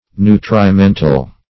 Nutrimental \Nu`tri*men"tal\, a.